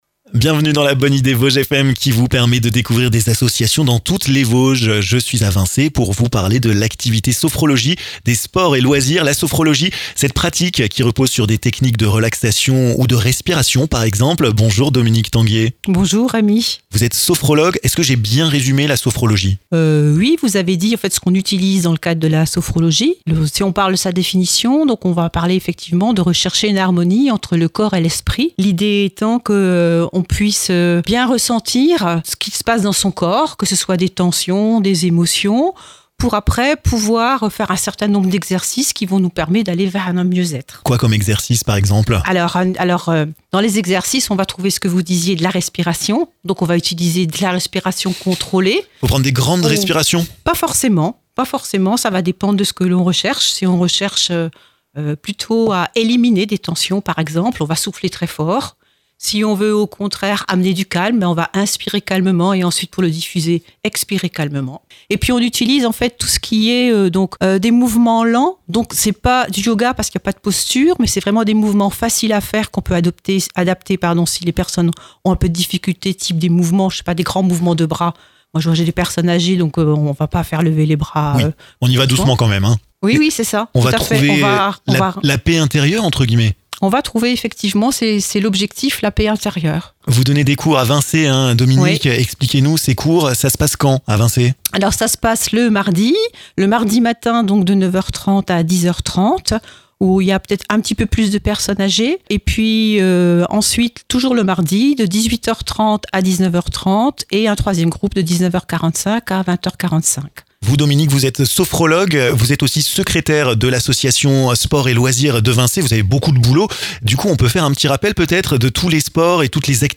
%%La rédaction de Vosges FM vous propose l'ensemble de ces reportages dans les Vosges%%